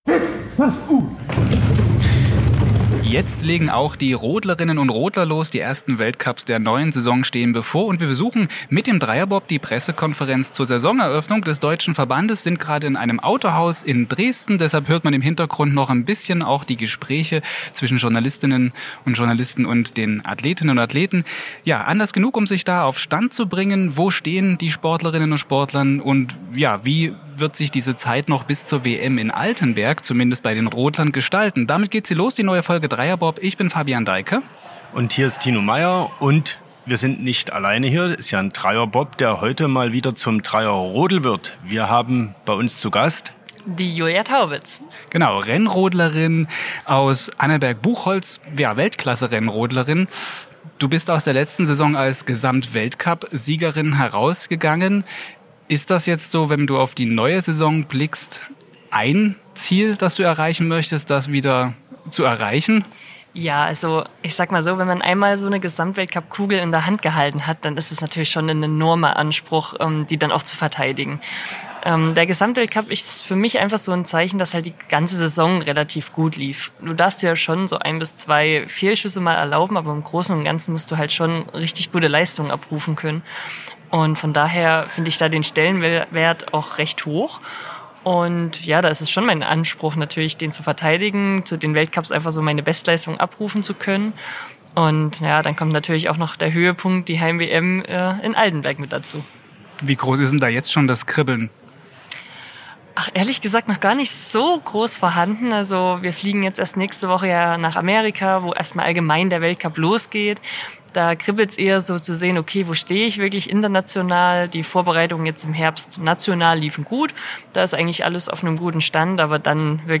Taubitz blickt im Podcast-Interview zurück auf ihren Karriere-Beginn als "nervige kleine Schwester", die ihrem Bruder überall hin folgte - und wie in Oberwiesenthal alles begann. Die 27-Jährige erzählt von Rückschlägen wie dem Sturz bei Olympia 2022, und sie verrät, wie sie dem Druck als neue Nummer eins im deutschen Team begegnet.